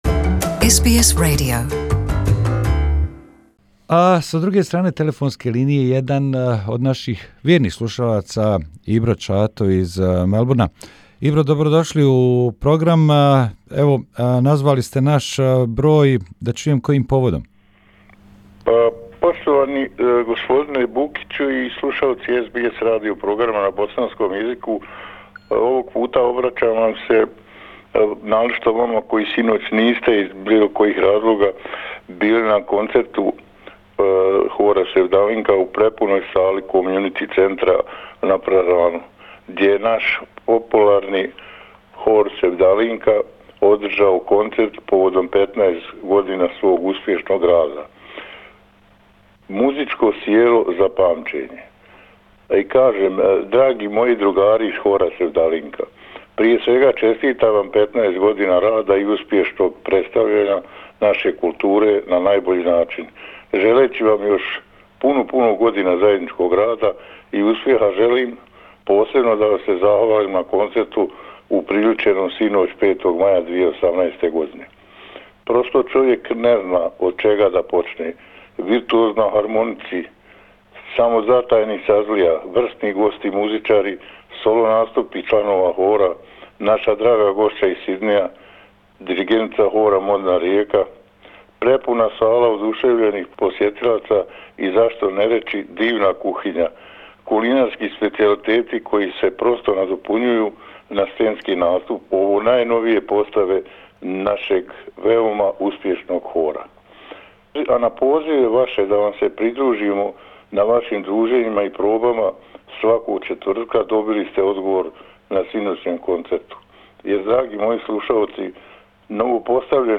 Commentary by one of the listeners about the concert of the Bosnian and Herzegovinian choir Sevdalinka, held last night in Melbourne.